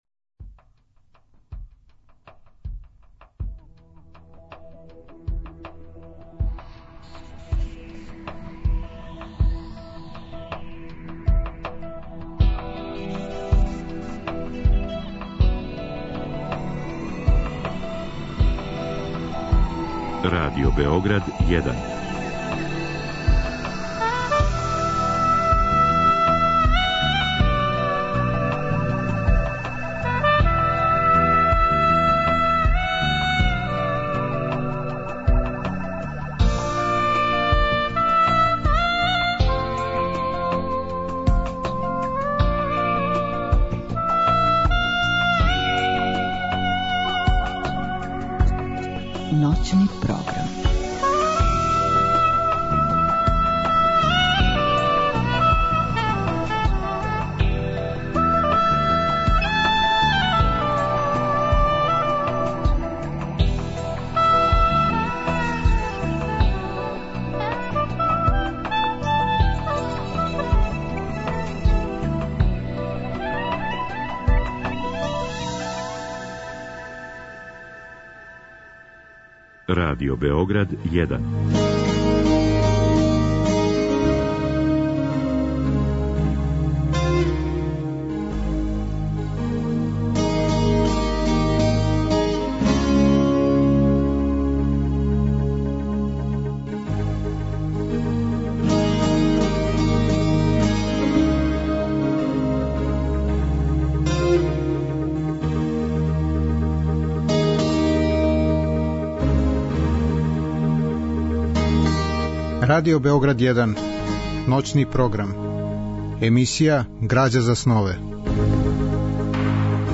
Гости су људи из различитих професија, они који су и сами ствараоци, и блиска им је сфера духа и естетике. Разговор и добра музика требало би да кроз ову емисију и сами постану грађа за снове.